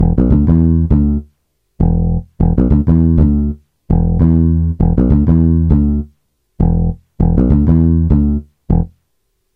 Loops basses hiphop - 100
SONS ET LOOPS GRATUITS DE BASSES HIPHOP MUSIC 100bpm